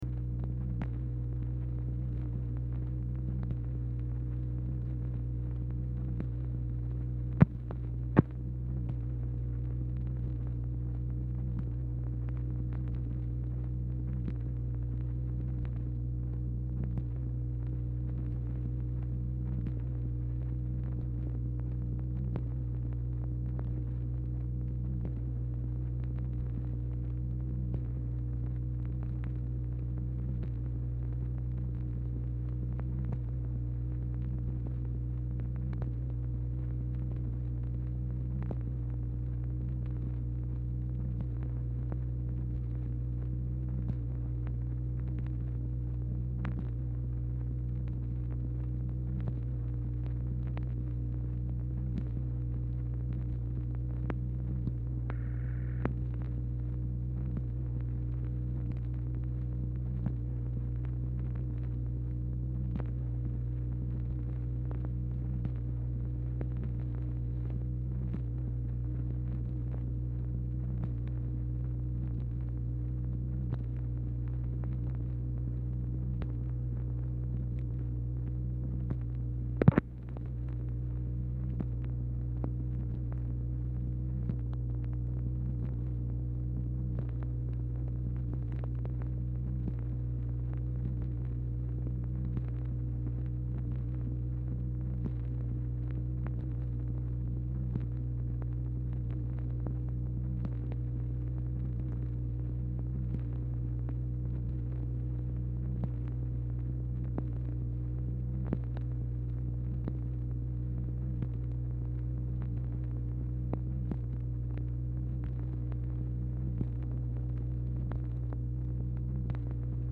Telephone conversation # 1138, sound recording, MACHINE NOISE, 1/1/1964, time unknown | Discover LBJ
Format Dictation belt
Specific Item Type Telephone conversation